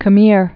(chə-mîr, shə-)